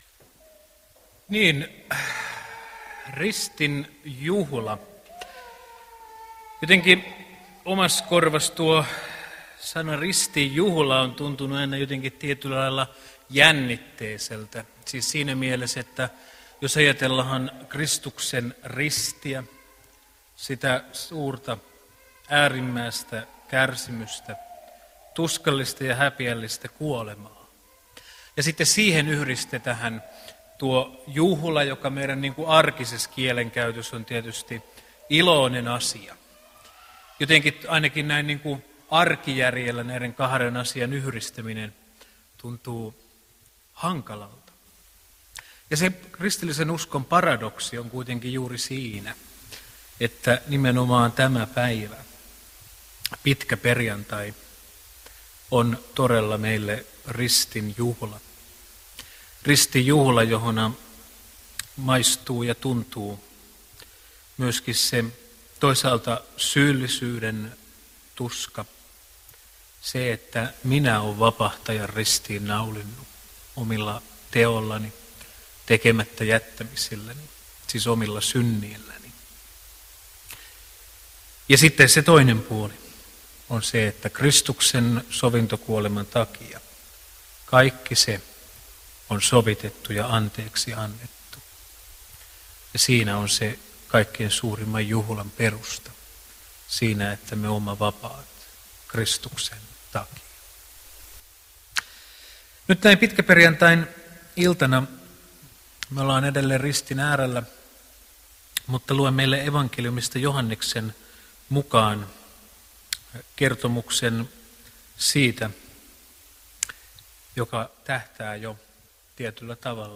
saarna Alavuden ristinjuhlassa pitkäperjantaina Tekstinä Joh. 19:31–42